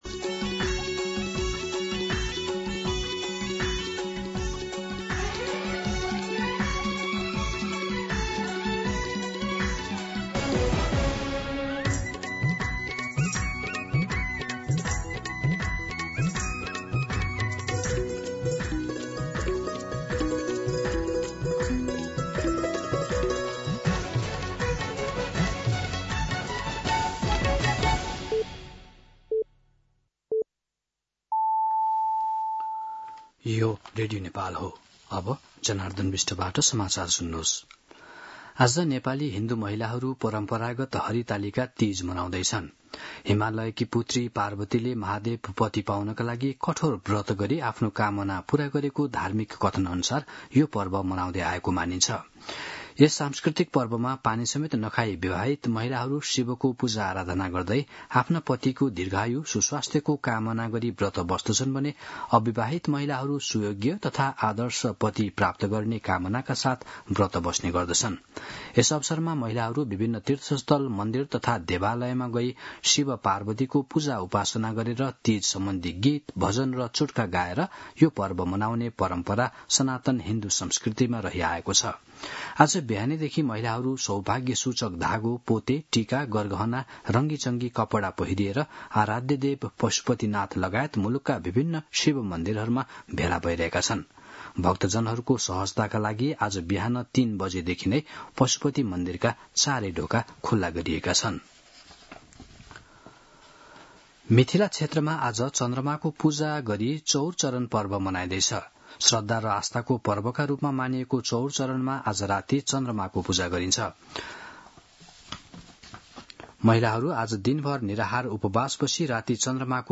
मध्यान्ह १२ बजेको नेपाली समाचार : १० भदौ , २०८२